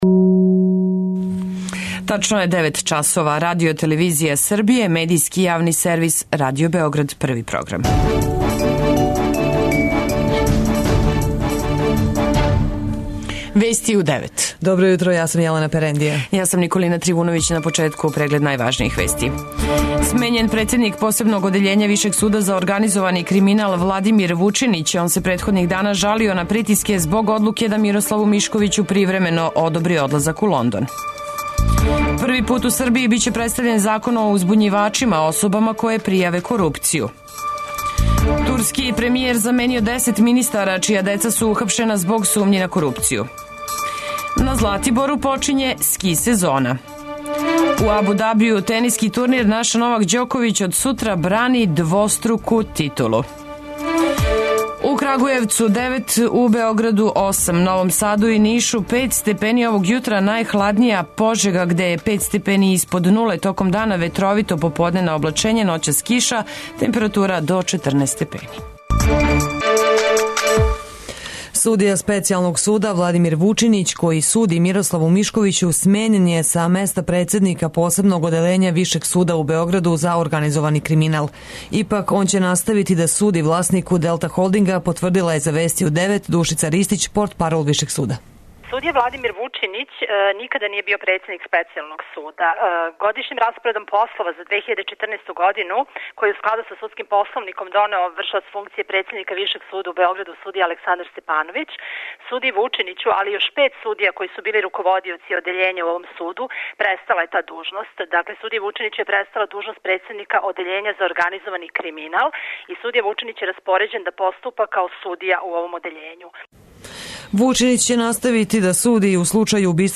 Уреднице и водитељке